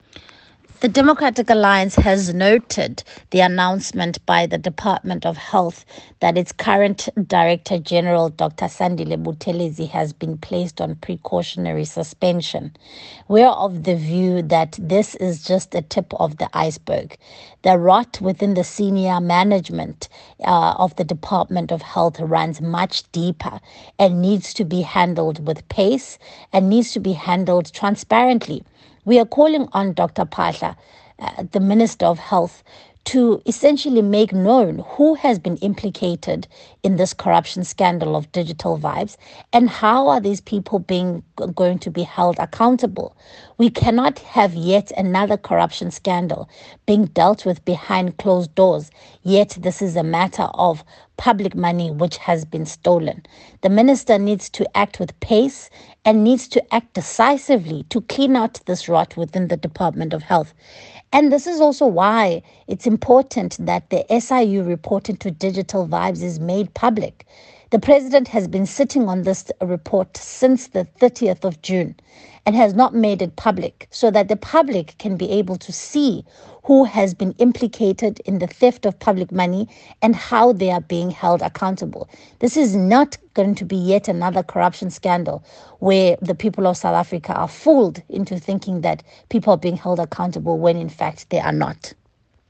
soundbite by Siviwe Gwarube MP